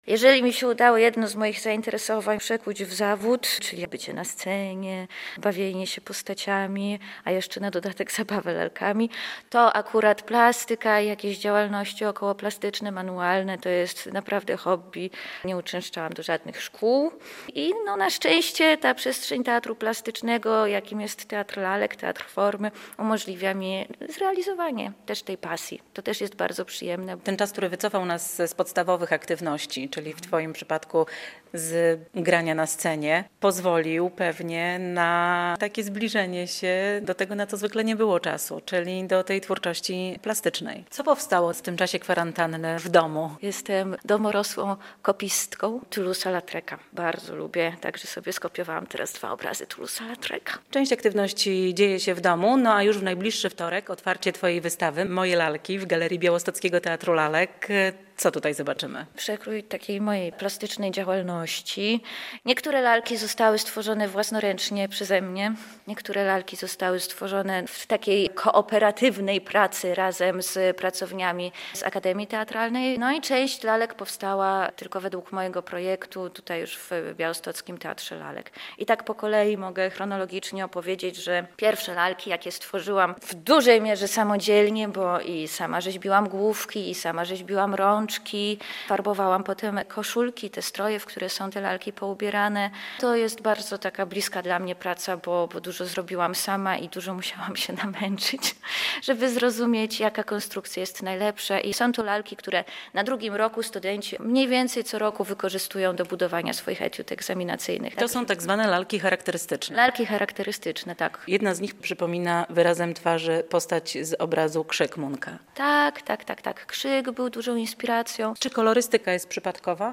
rozmawiała